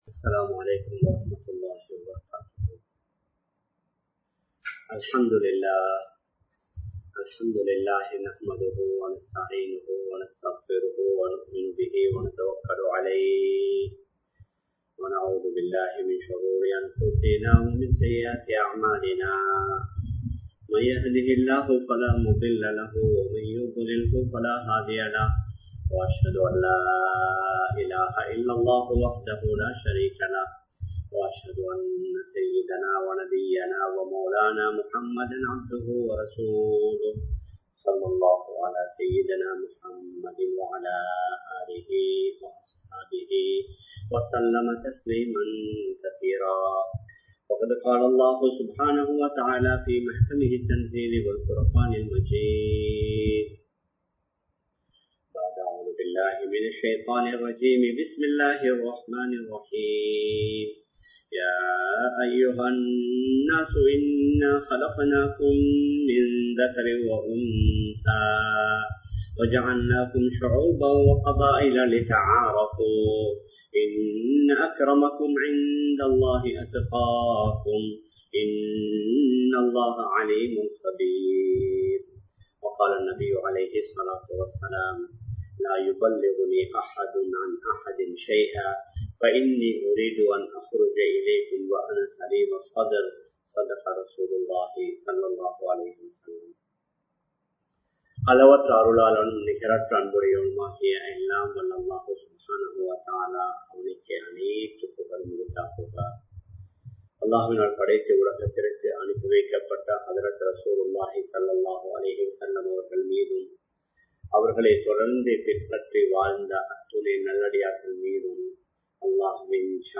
ஒற்றுமையின் அவசியம் (The need for unity) | Audio Bayans | All Ceylon Muslim Youth Community | Addalaichenai
Live Stream